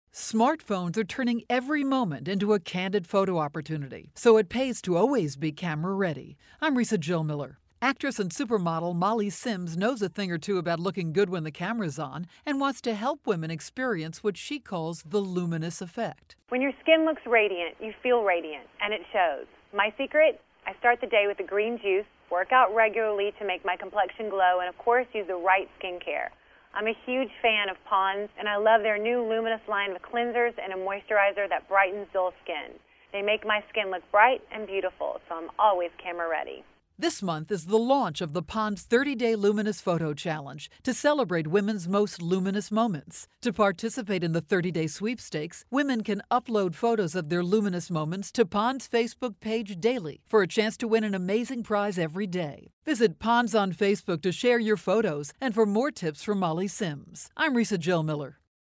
March 7, 2013Posted in: Audio News Release